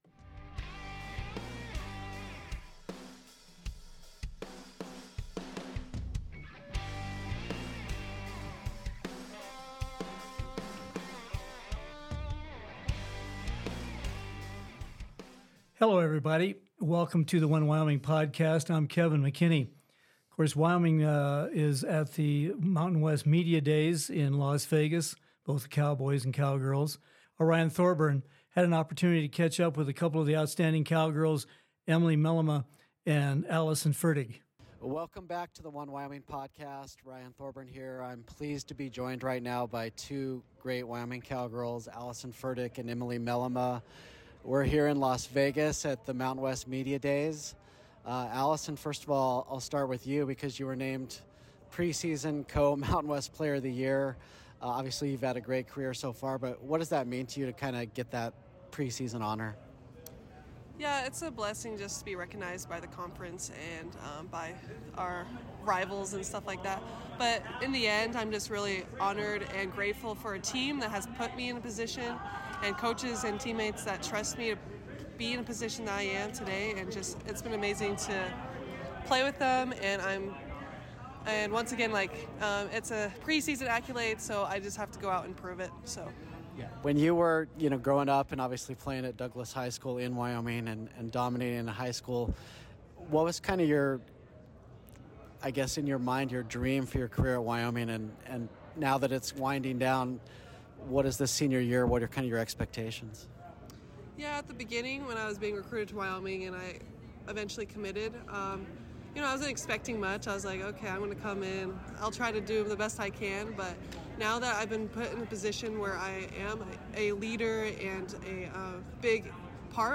Mountain West Basketball Media Day with Cowboy and Cowgirl Basketball